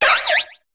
Cri d'Escargaume dans Pokémon HOME.